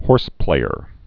(hôrsplāər)